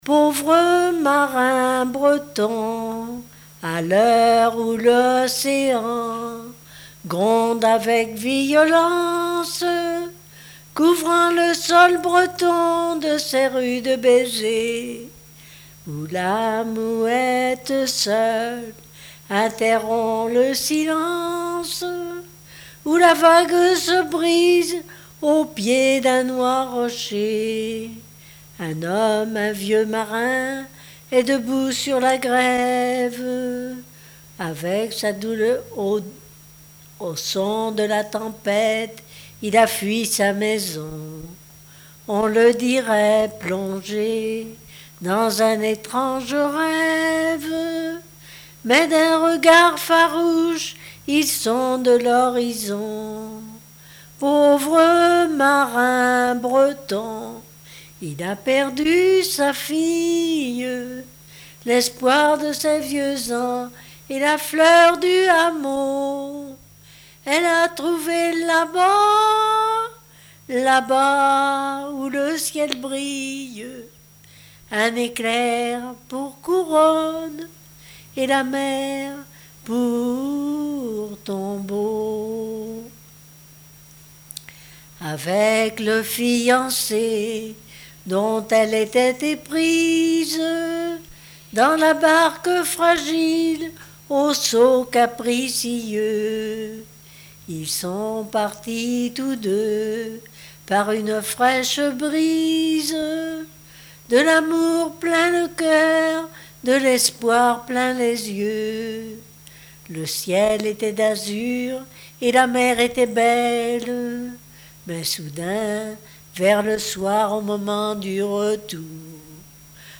chansons et historiettes
Pièce musicale inédite